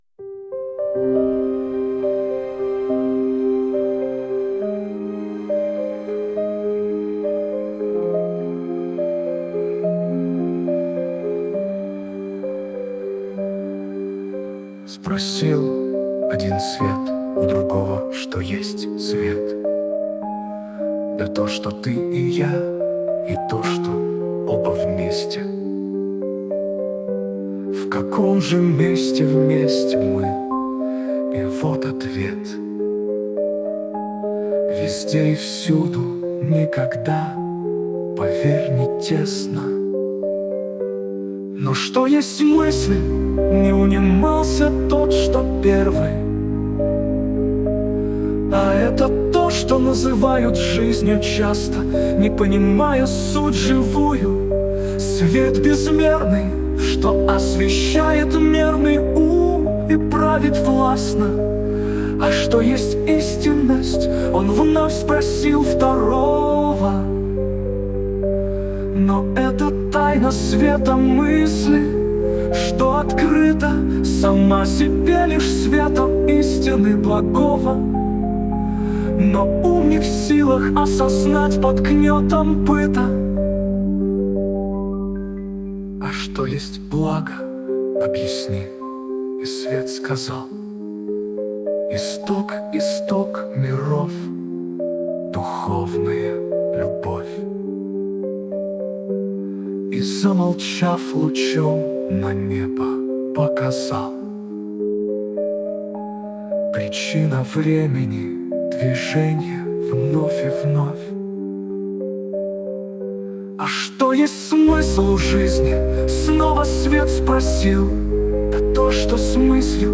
муз. композиция